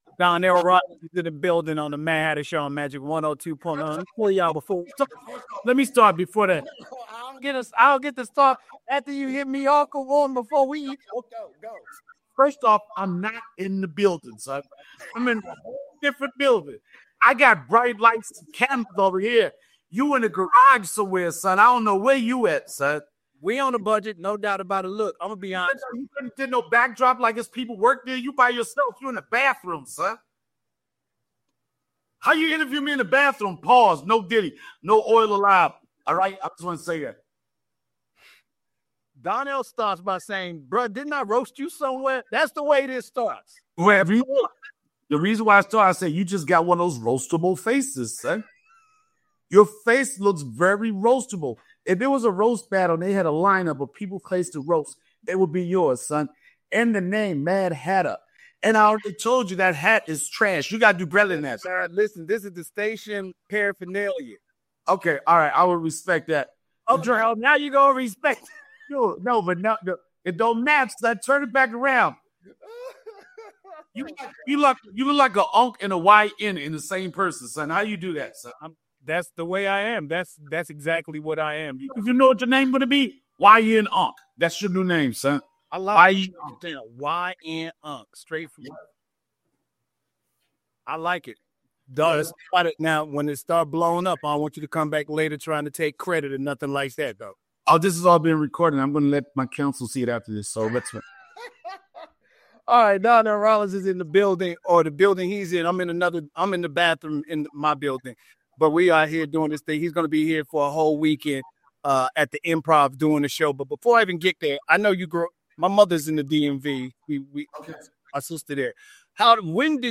Donnell breaks down his come-up in comedy, his unforgettable run as “Ashy Larry,” and how he’s kept the grind going through stand-up, TV, and film. This episode is straight jokes and gems—funny, fast-paced, and full of insight into the comedy game and longevity in entertainment.